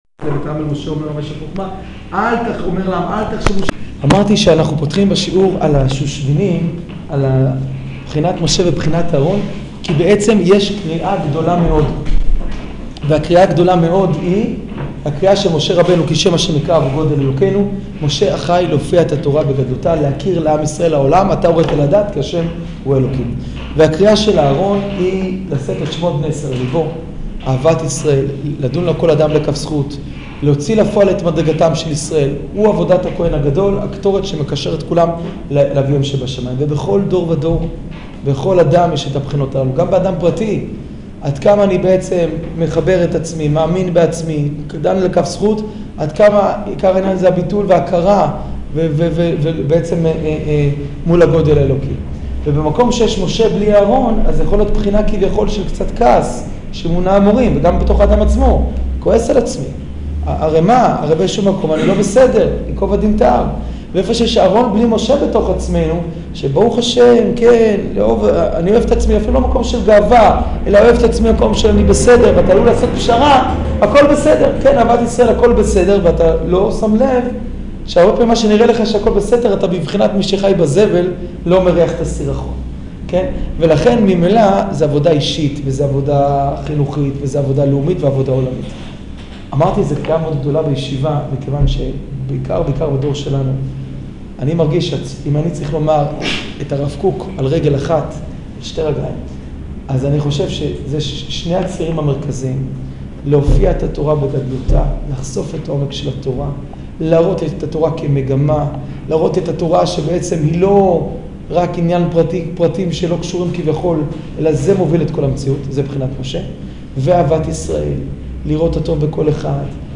שיעור מאמר שופרות